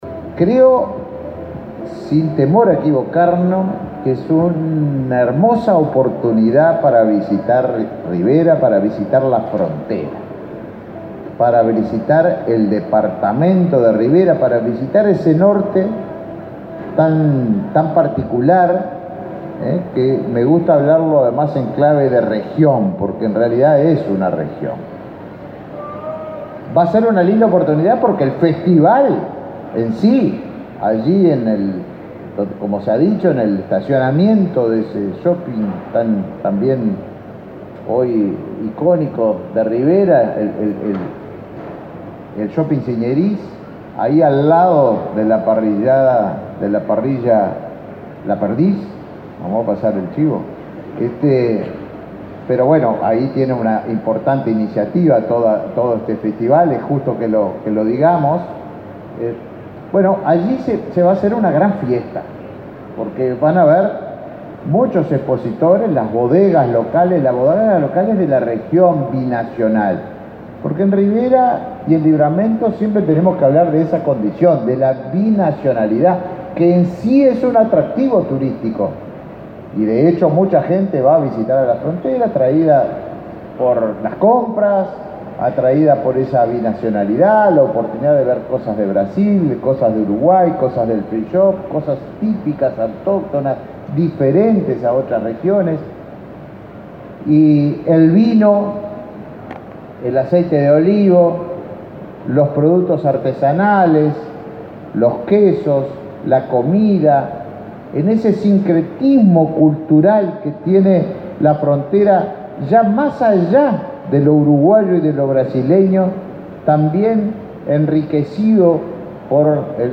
Palabras del ministro de Turismo, Tabaré Viera